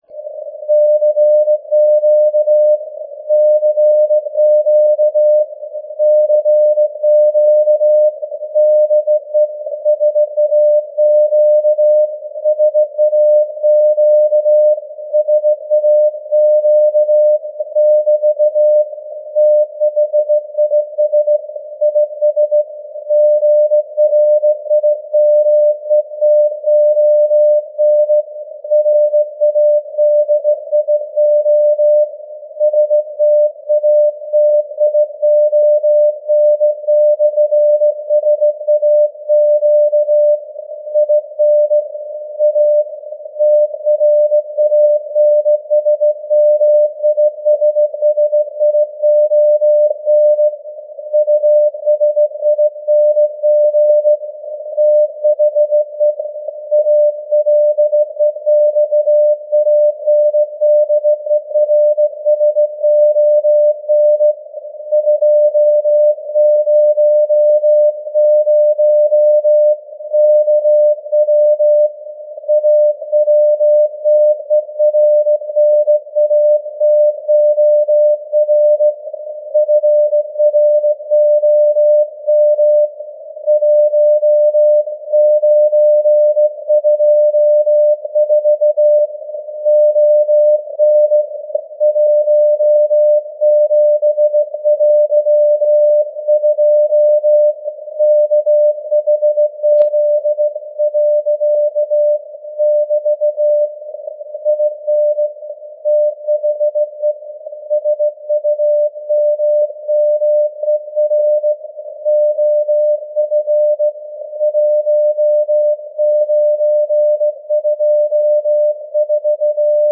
This is the complete CW message as received from the SAQ
This is the Alexanderson alternator, that directly   generates the 17.2 kHz signal, with a power of 200 kW.